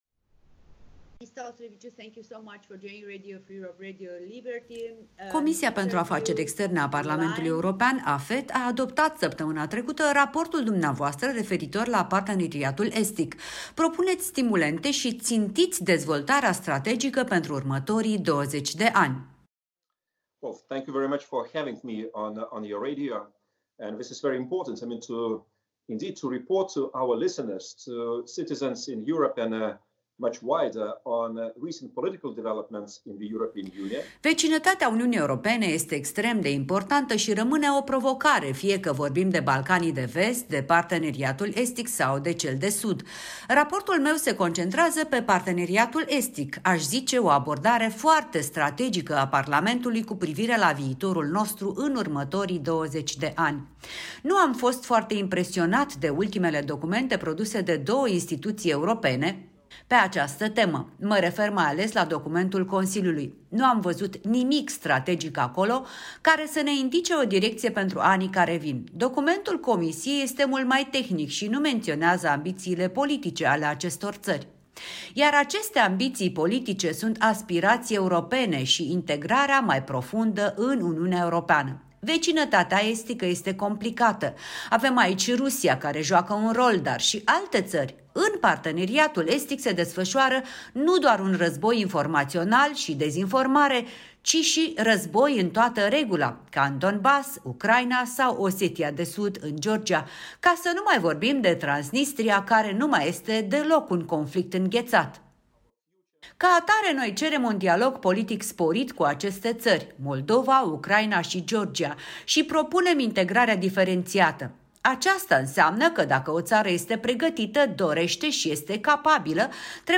Interviu cu Petras Auštrevičius